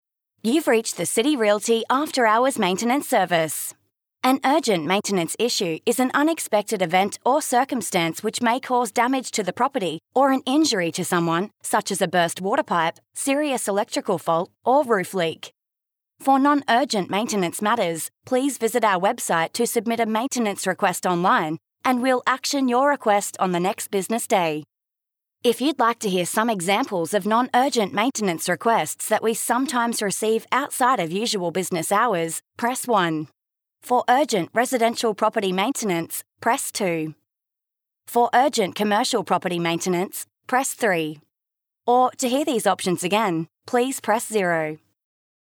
Below is the what the caller is advised when they ring the afterhours line.